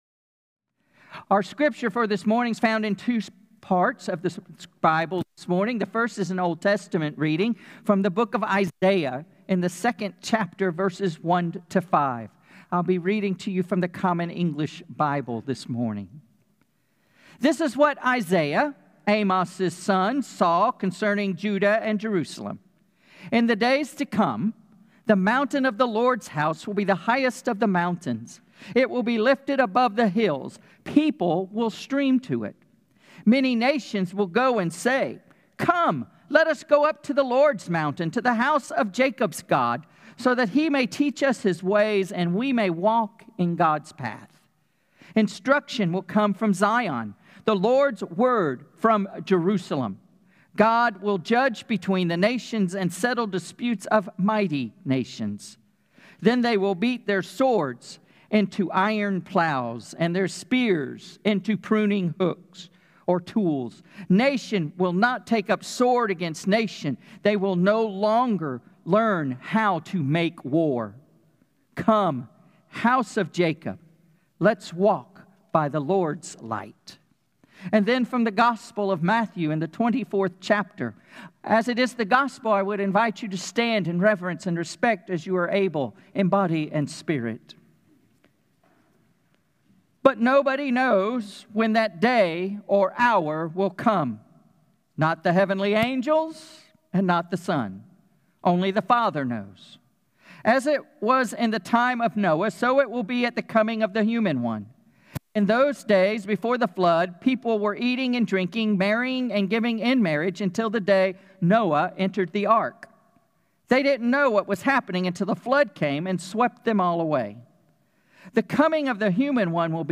Christmas Homily